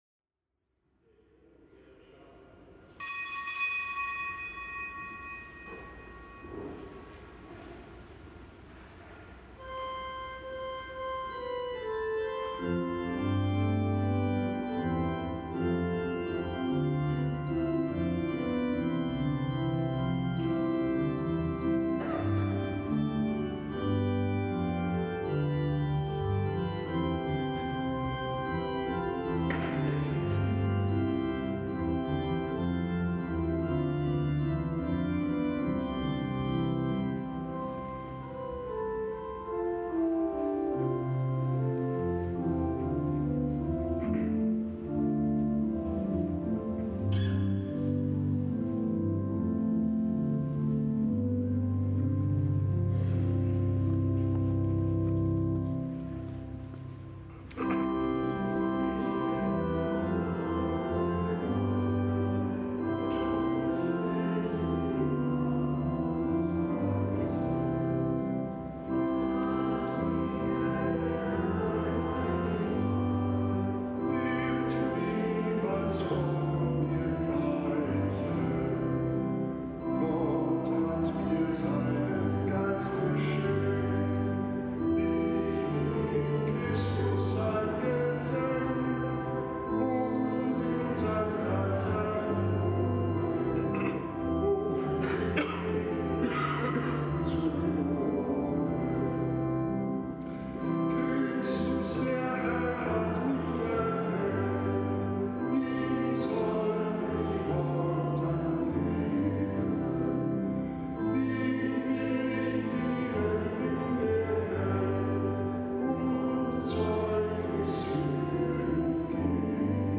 Kapitelsmesse aus dem Kölner Dom am Donnerstag der dritten Osterwoche.